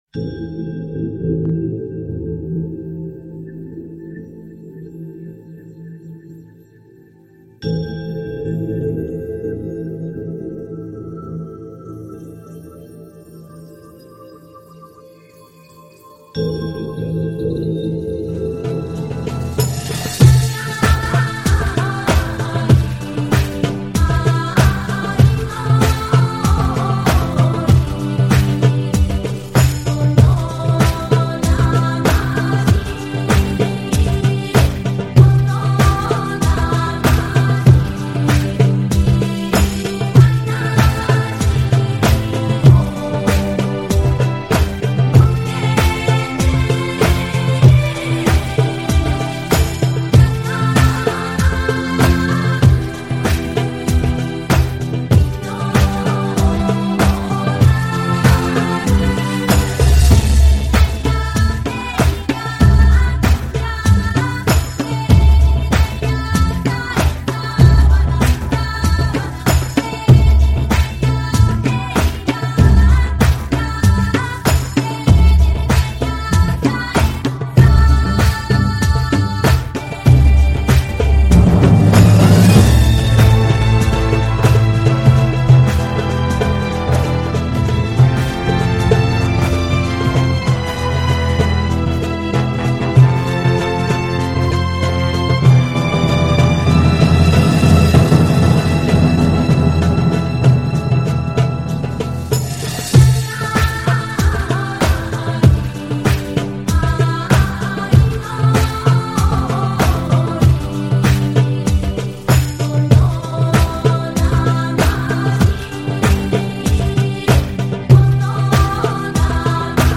等到2:34秒女声的吟唱乍起，瞬间被沦陷了，才分外能体会旋律舒展的美妙，和那种辽阔感，是可以穿越千年岁月的声音啊…
飘渺空灵，岁月的车轮向前转动，尔后，音乐气势澎湃，雄浑激荡，
此曲节奏暗含人生哲理，前期悠扬低沉，预示新生命诞生，
慢慢的变得轻快明朗，象征指点江山激扬文字的青年时期，
随之节奏低沉闭塞，昭示遇到挫折挑战，
悠扬的钟声和舒缓的琴声